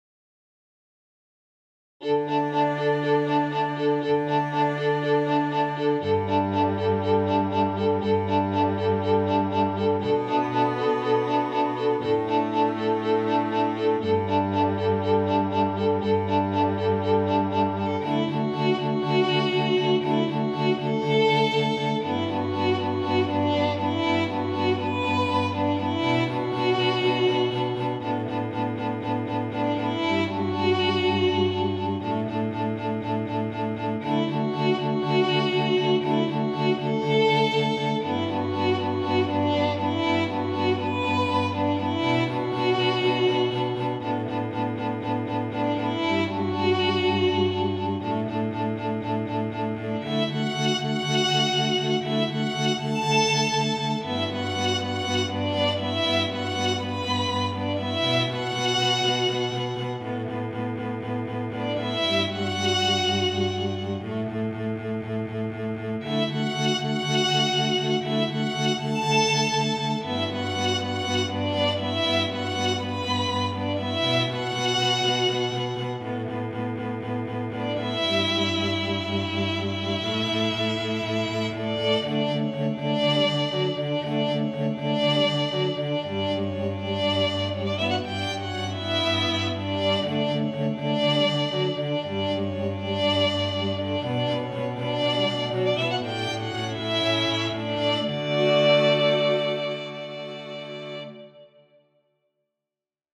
para Quarteto de Cordas
● Violino I
● Violino II
● Viola
● Violoncelo